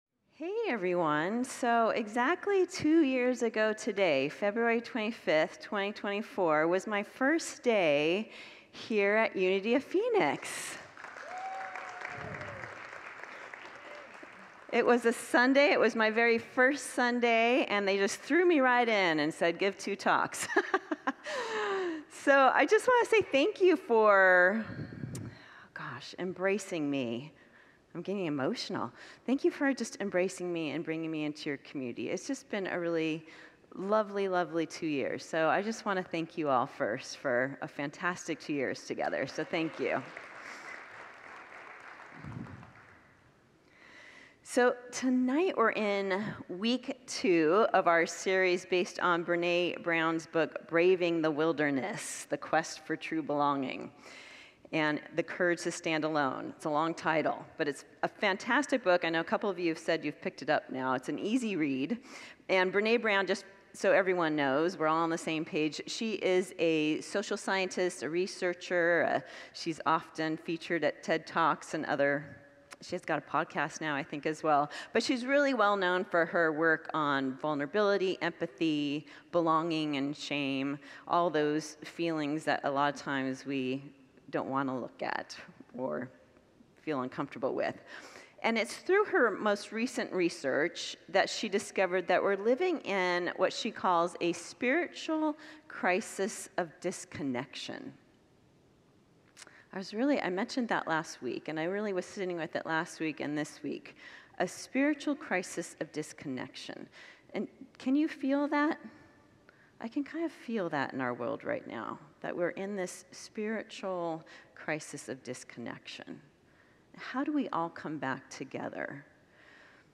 Series: Wednesday Evening Worship